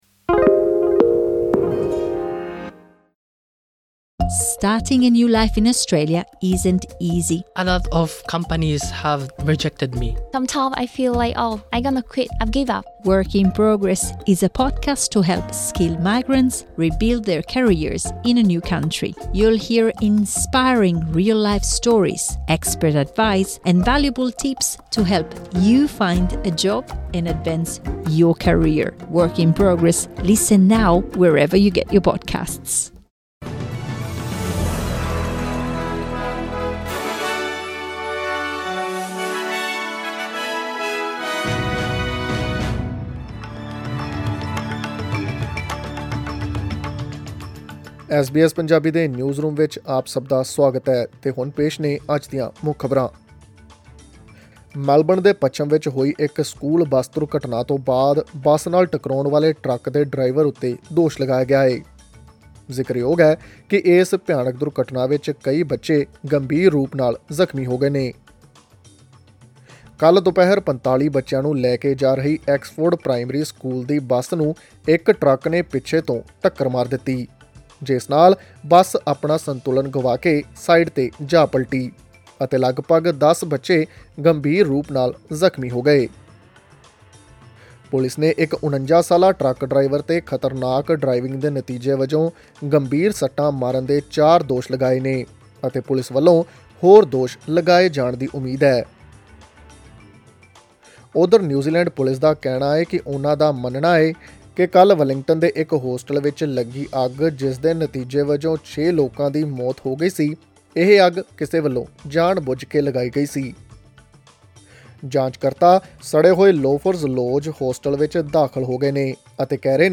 ਅੱਜ ਦੀਆਂ ਪ੍ਰਮੁੱਖ ਰਾਸ਼ਟਰੀ ਅਤੇ ਅੰਤਰਰਾਸ਼ਟਰੀ ਖਬਰਾਂ ਦੀ ਤਫ਼ਸੀਲ ਜਾਨਣ ਲਈ ਸੁਣੋ ਐਸ ਬੀ ਐਸ ਪੰਜਾਬੀ ਦੀ ਆਡੀਓ ਰਿਪੋਰਟ।